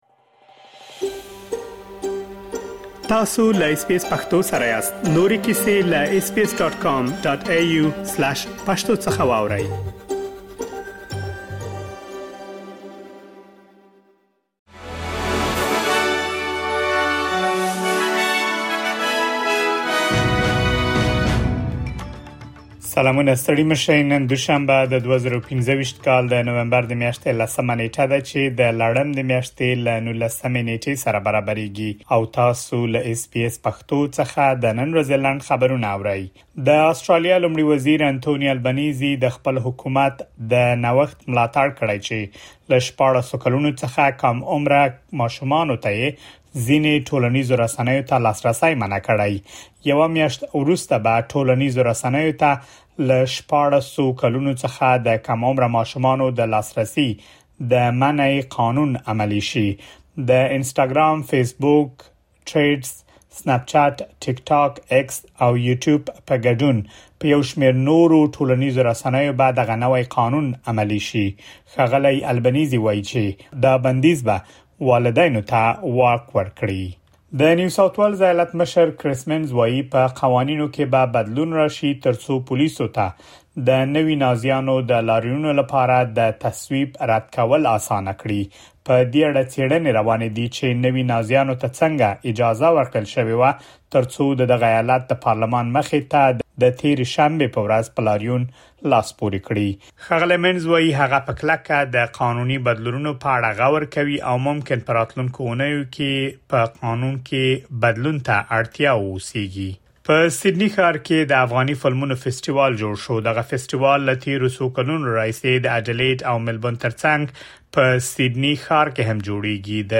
د اس بي اس پښتو د نن ورځې لنډ خبرونه |۱۰ نومبر ۲۰۲۵